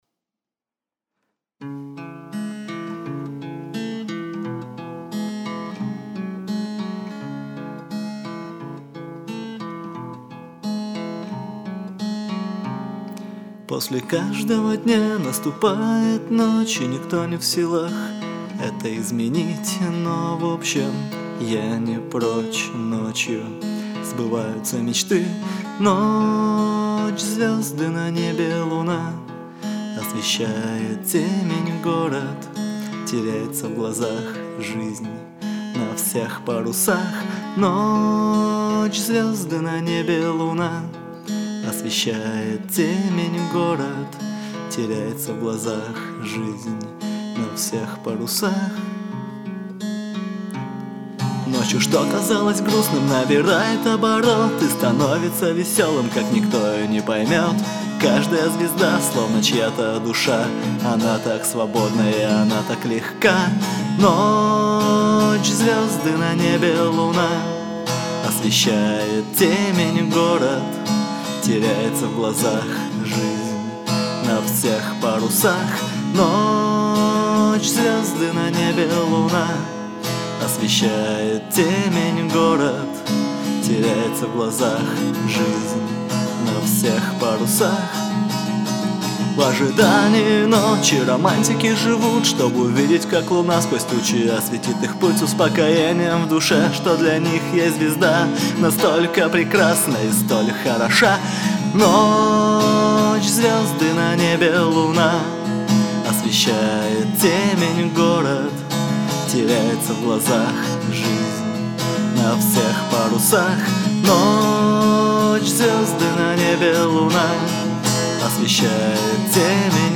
Первый студийный альбом.